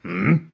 squashHmm.ogg